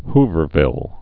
(hvər-vĭl)